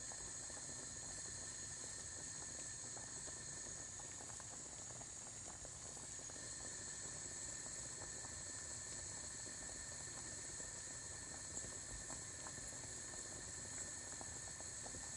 Cooking rice
描述：Cooking rice in a rice cooker. Ideal for kitchen sounds but also for stimulating the sound of a magic potion brewing in a witch's black pot Sony PCMD50
标签： boiling cooking magic potion rice ricecooker soup water witch wizard
声道立体声